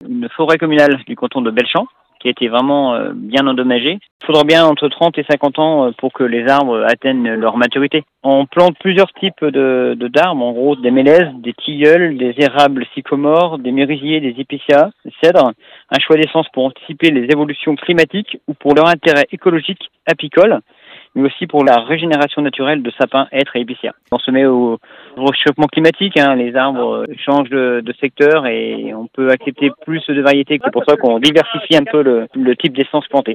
Sébastien Briand, le maire des Clefs : Télécharger le podcast Partager :